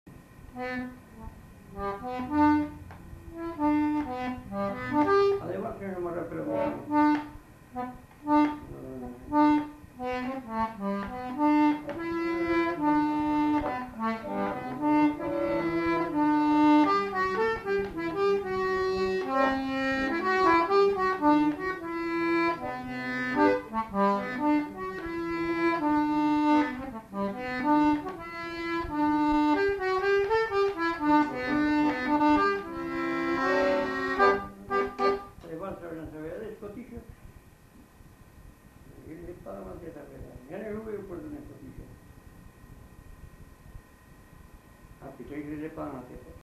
Valse
Aire culturelle : Marmandais gascon
Lieu : Mas-d'Agenais (Le)
Genre : morceau instrumental
Instrument de musique : accordéon diatonique
Danse : valse